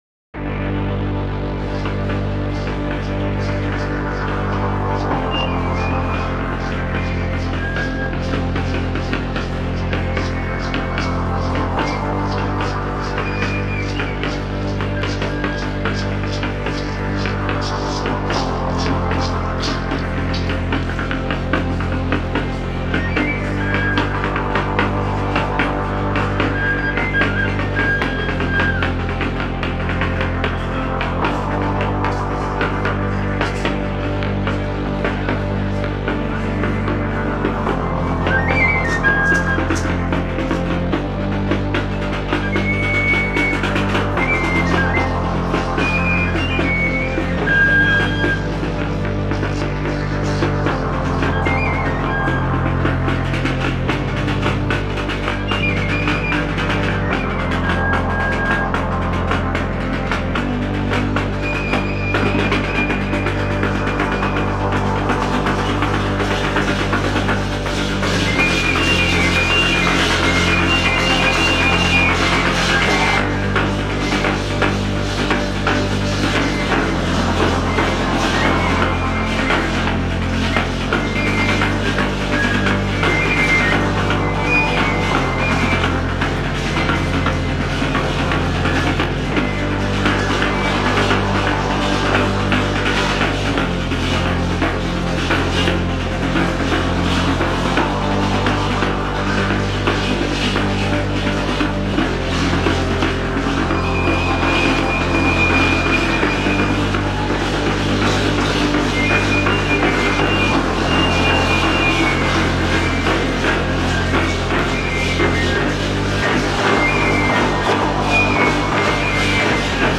"“Parachicos” is a piece that tries to merge a traditional Mexican dance from the 17th century with the contemporary sounds of electronic music. By intertwining beats and synthesizers with the notes of reed flutes and collective drumming, a new party is generated —a new dance....